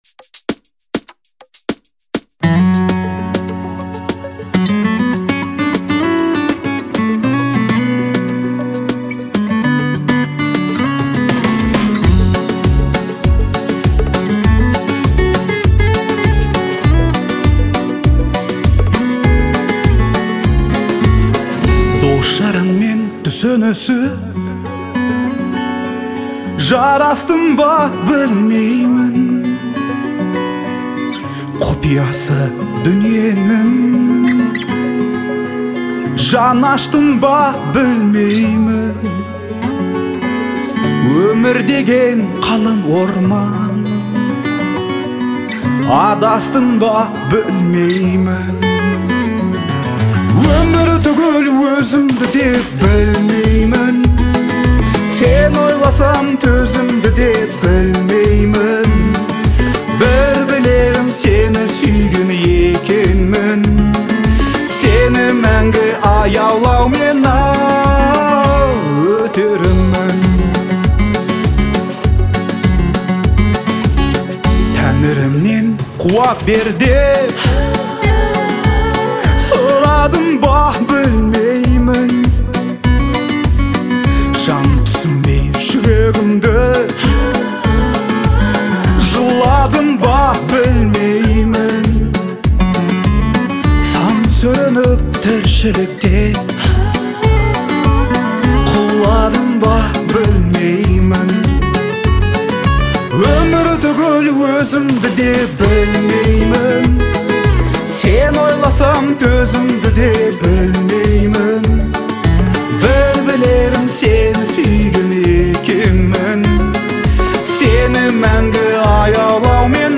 казахского поп-фолка